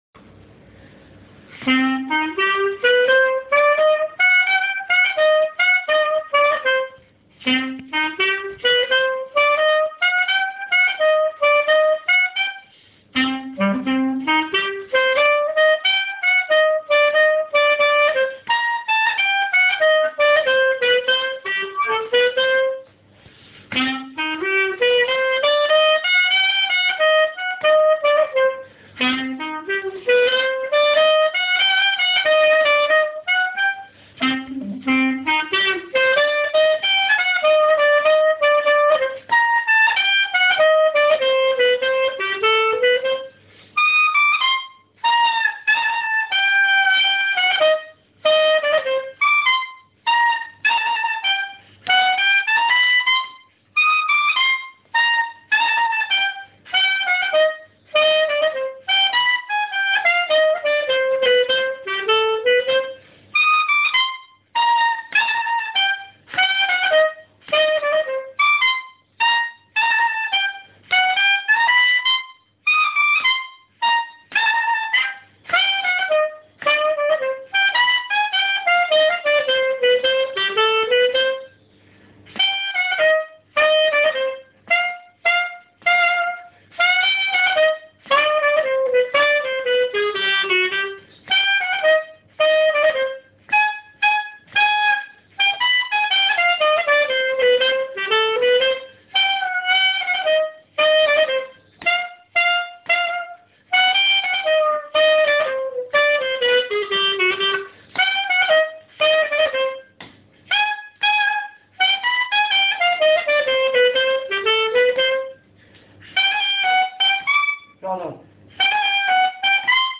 scotich.mp3